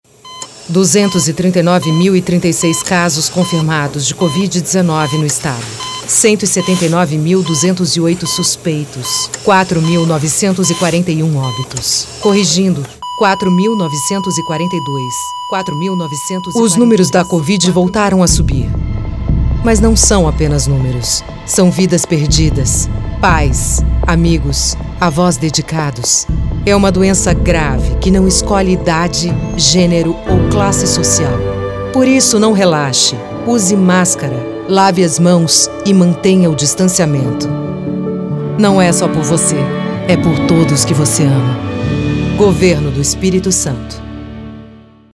Feminino
Voz Padrão - Grave 00:45